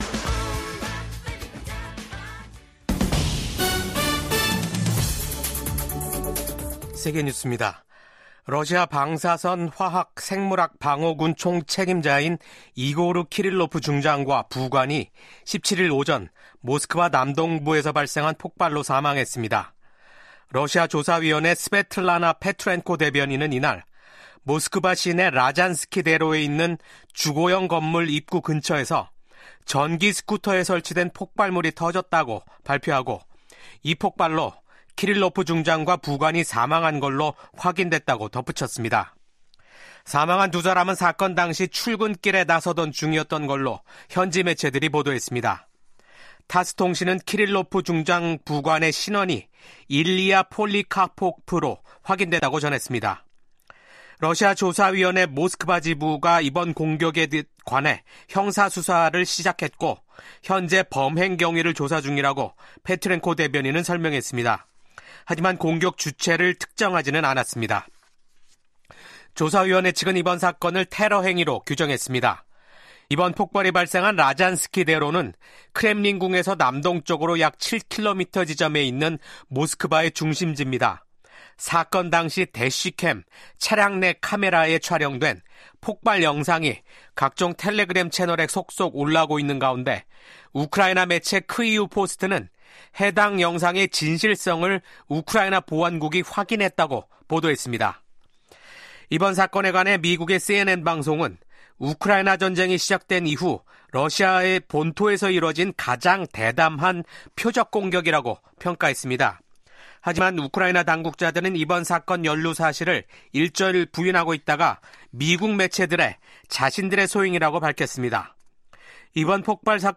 VOA 한국어 아침 뉴스 프로그램 '워싱턴 뉴스 광장'입니다. 미국 국무부는 미한 동맹이 윤석열 한국 대통령의 직무 정지에 영향을 받지 않을 것이라고 강조했습니다. 윤석열 대통령에 대한 탄핵소추안 가결에 따른 한국 내 권력 공백 상태가 미한 관계에 주는 영향은 제한적일 것이라는 분석이 나오고 있습니다.